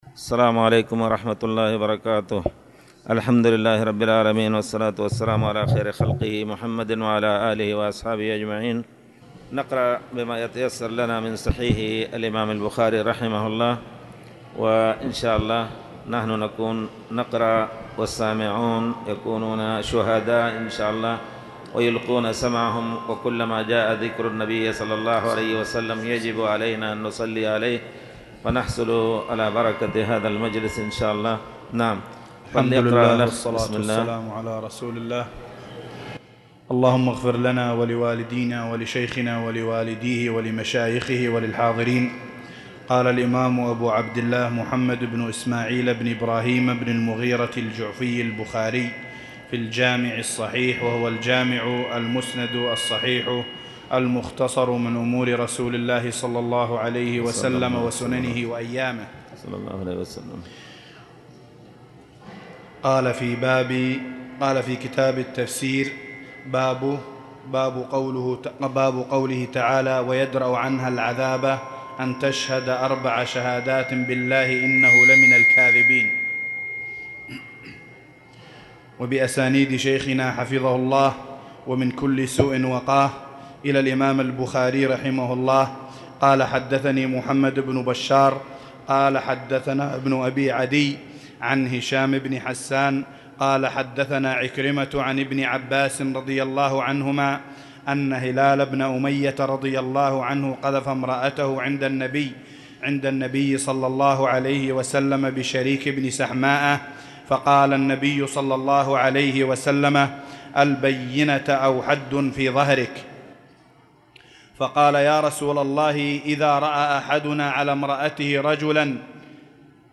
تاريخ النشر ٢٨ محرم ١٤٣٨ هـ المكان: المسجد الحرام الشيخ